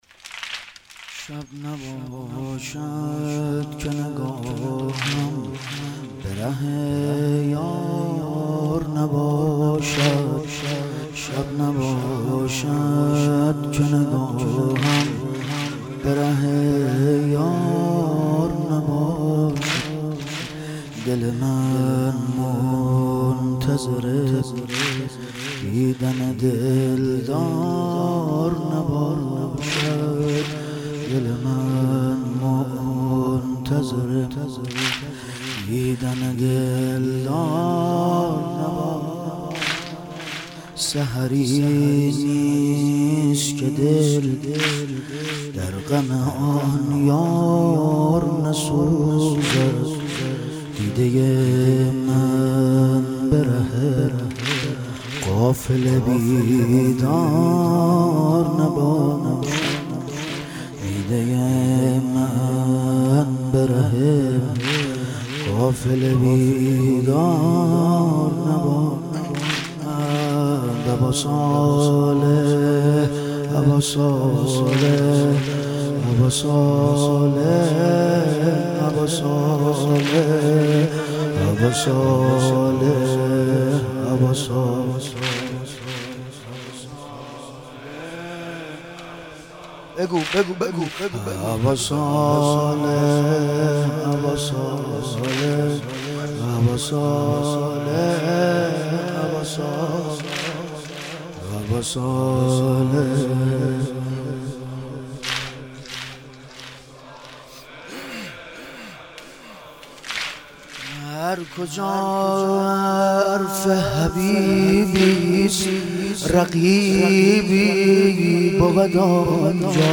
ایام فاطمیه اول - واحد - 5 - 1399